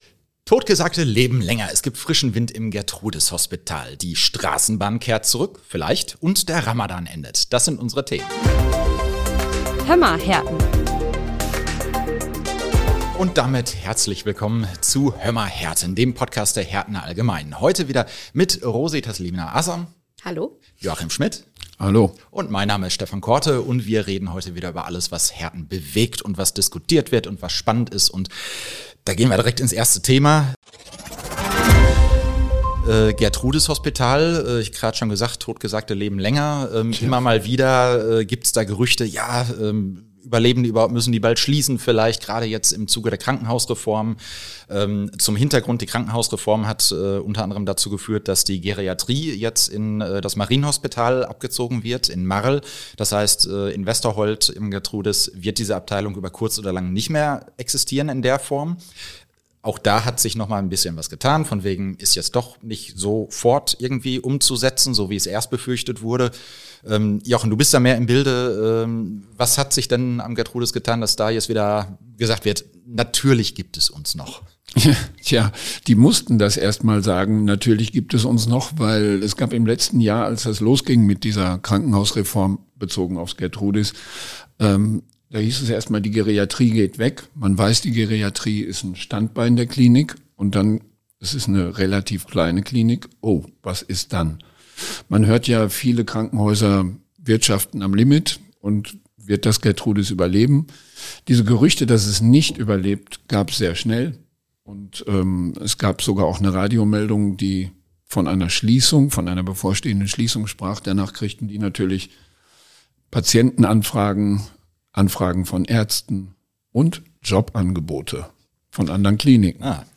Wir diskutieren jede Woche über all das, was Herten bewegt.